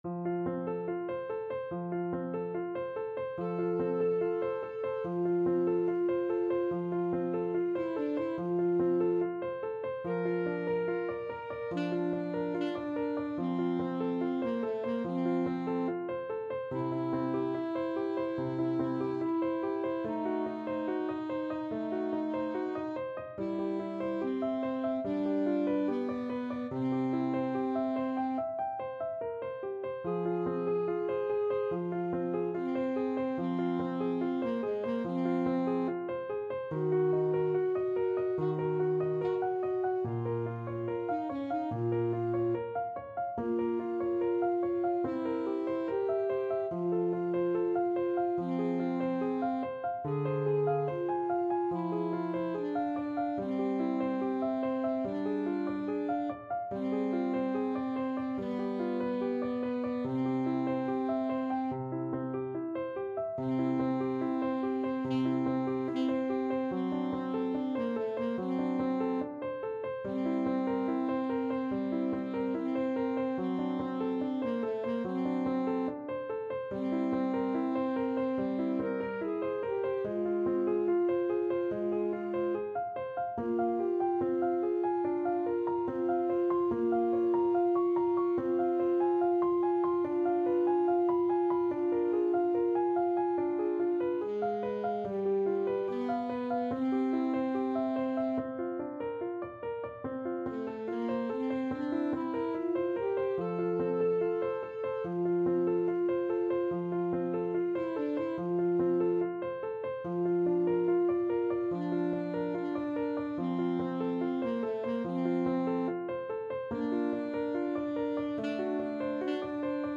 Andante =72
Classical (View more Classical Alto Saxophone Duet Music)